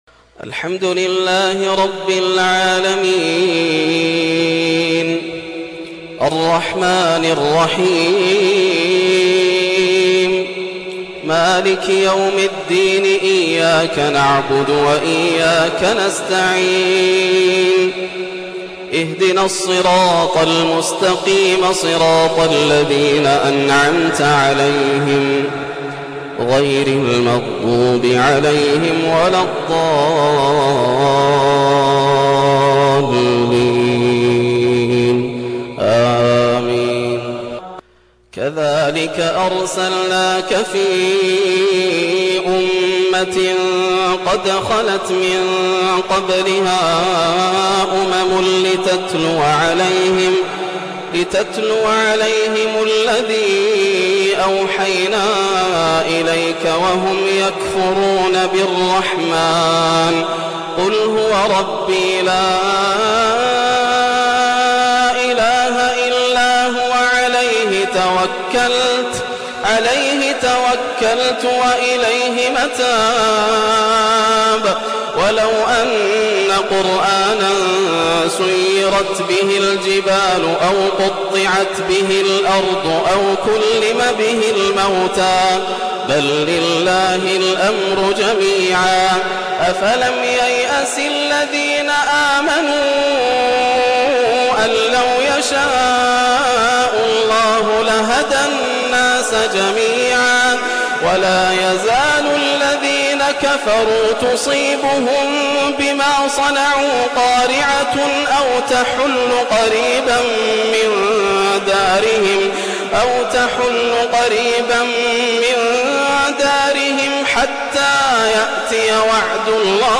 ماتيسر من سورة الرعد - أجمل قراءة تفسيرية عشائية 1430 > عام 1430 > الفروض - تلاوات ياسر الدوسري